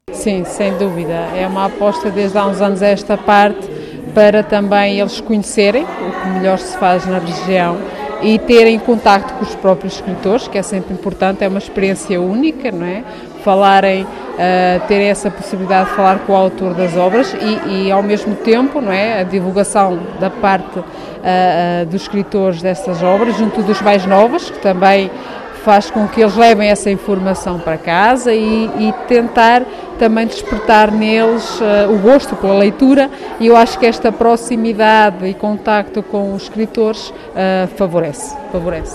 Na apresentação também esteve a vereadora com o pelouro da Educação, Sónia Salomé, salientando que é importante incutir habitos de leituras aos mais pequenos: